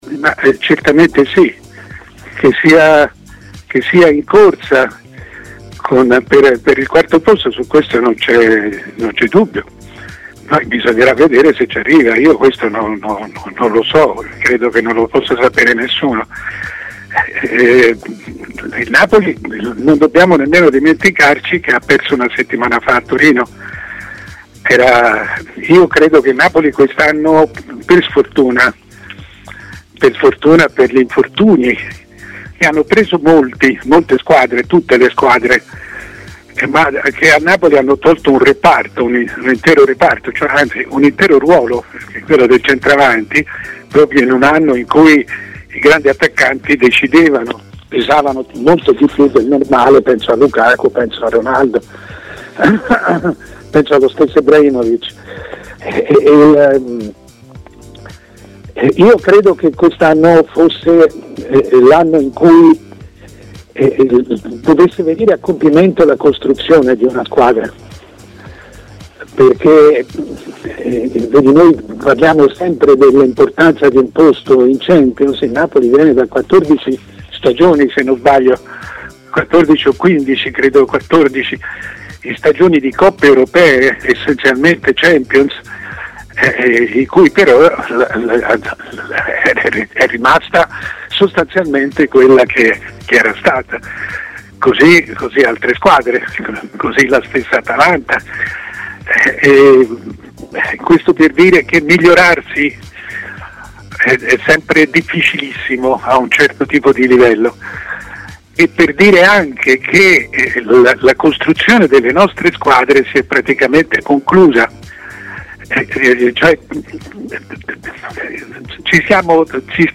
Mario Sconcerti, prima firma del giornalismo sportivo italiano, ha così parlato in diretta a Stadio Aperto, trasmissione di TMW Radio, analizzando la situazione del campionato di Serie A: "Il punto è che in Italia ci si accontenta di perdere bene e non più vincere, per tanti anni ci ha pensato la Juve e tutti a starle dietro.